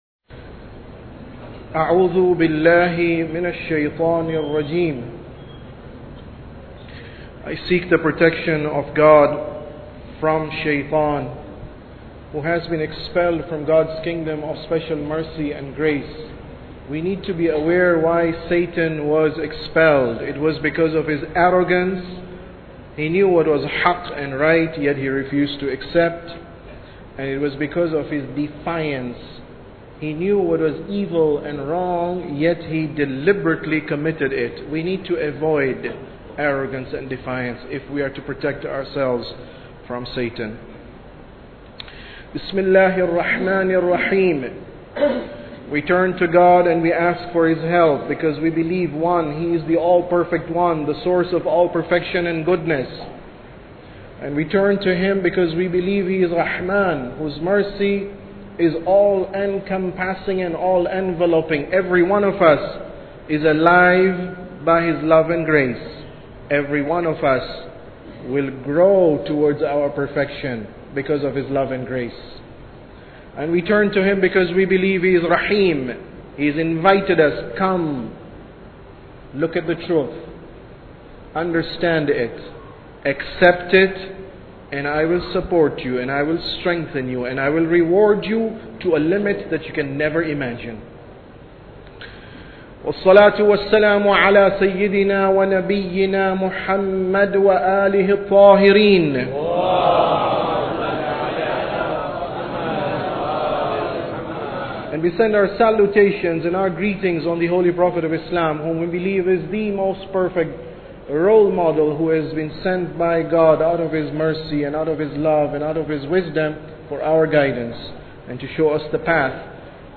Sermon About Tawheed 2